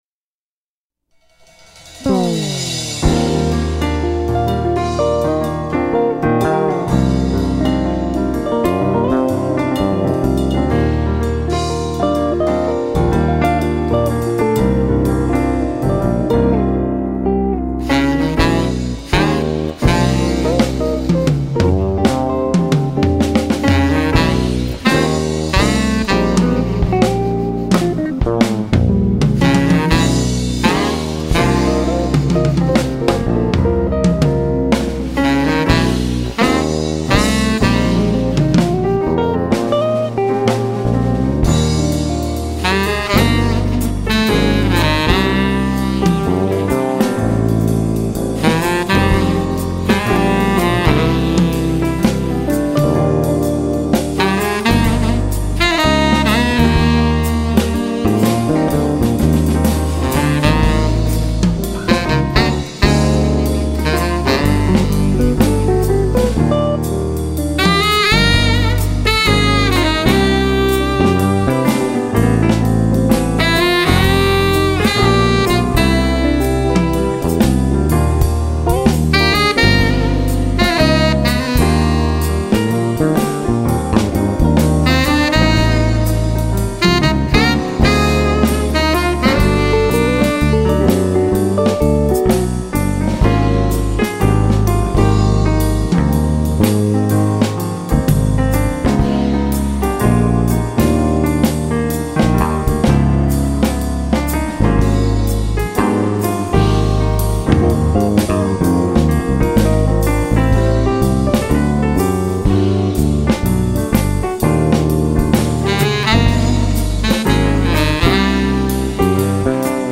2197   03:57:00   Faixa:     Jazz
Bateria
Teclados
Baixo Acústico
Saxofone Tenor
Guitarra